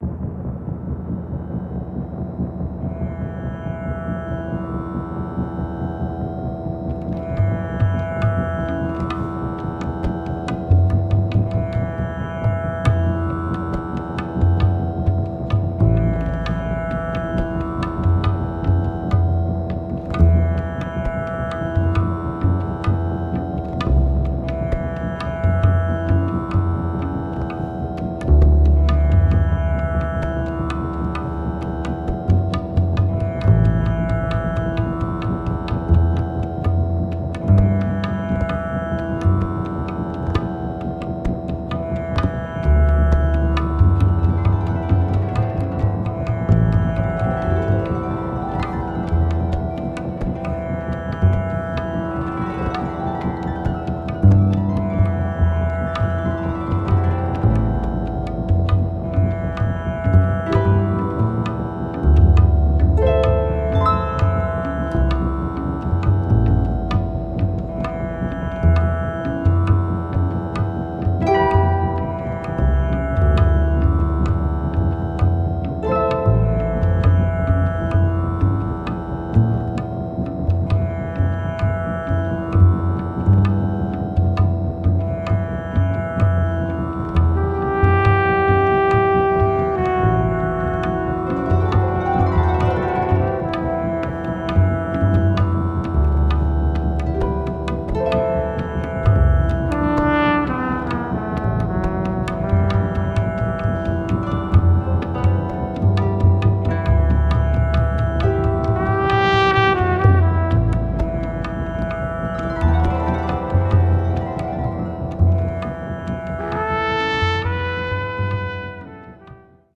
treated C trumpet, percussion, conch shell
electric & acoustic bass
tabla & djembe
moog
harp